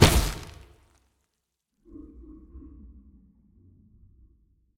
BodyFall.ogg